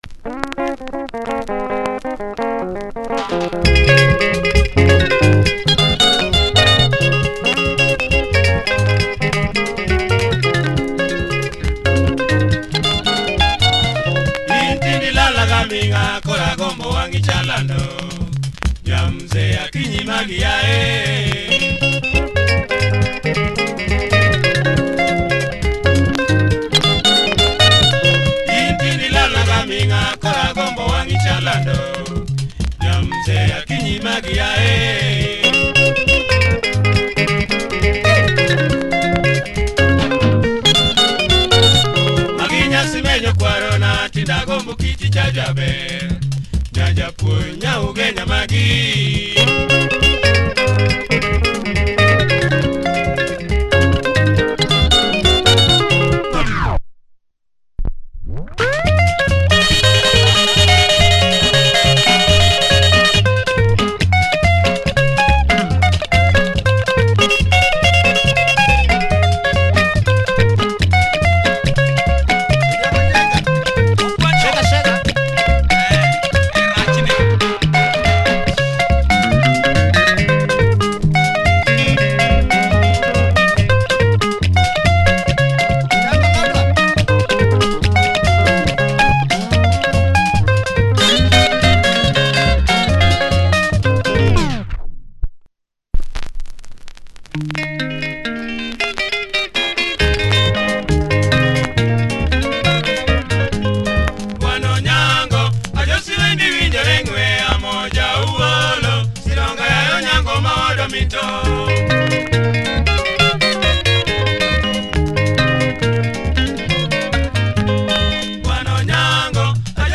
Luo Benga by this famous group